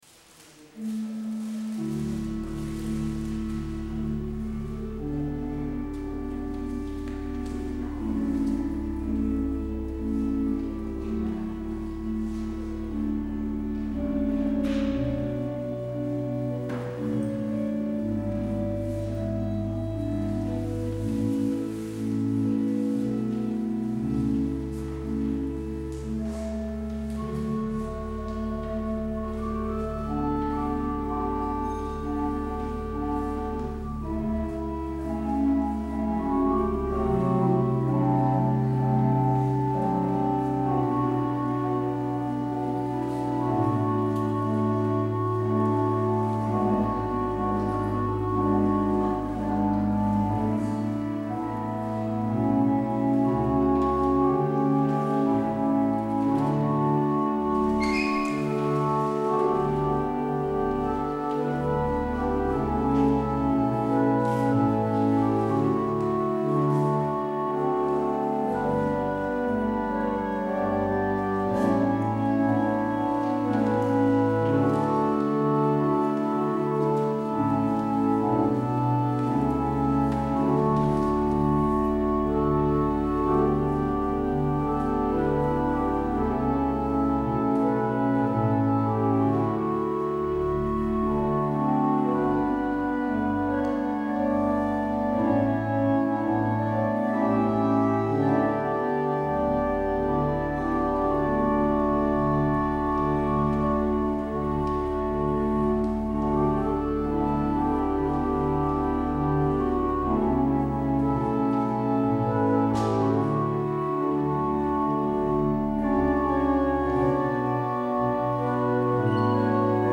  Luister deze kerkdienst terug
Als openingslied kunt u luisteren naar Psalm 103: 1 en 5.